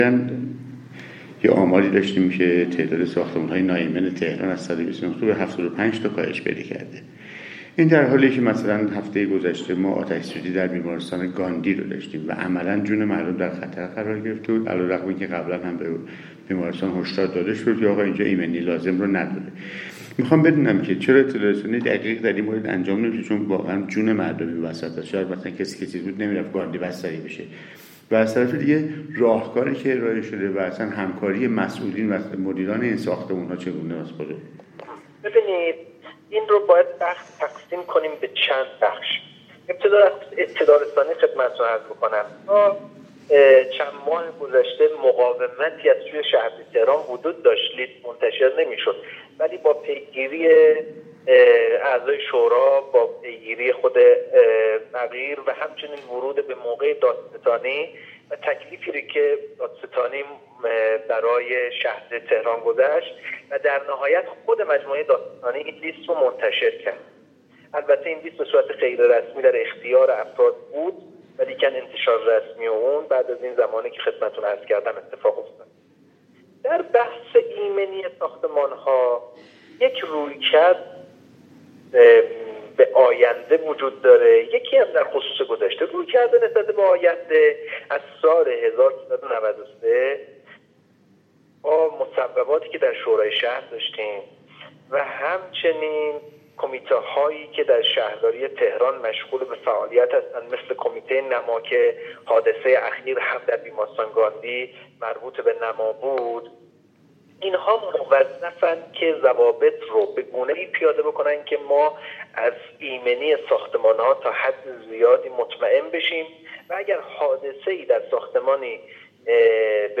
مهدی عباسی رییس کمیسیون شهرسازی شورای شهر تهران در گفتگو با خبرآنلاین در خصوص حادثه اخیر در بیمارستان گاندی گفت که این حادثه مربوط به نمای این بیمارستان بوده است.